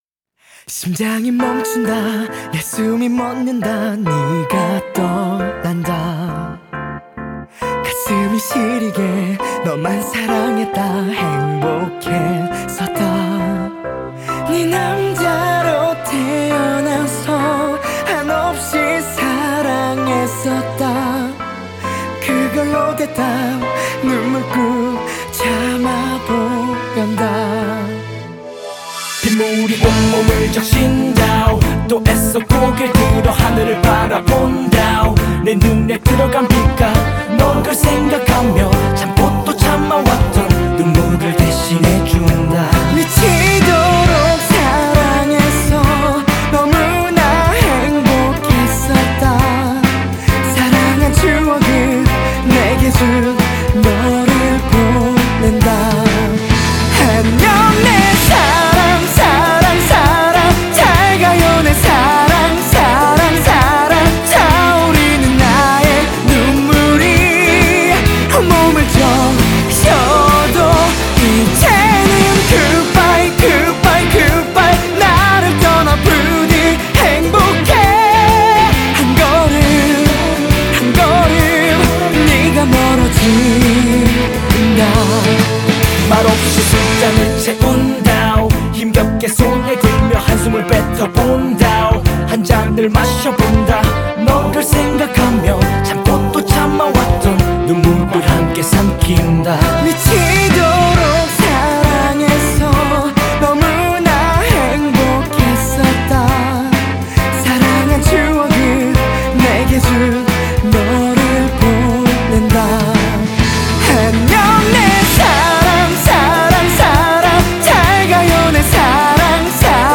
😀 Your cello-ing sounds so deft and the sound is so rich.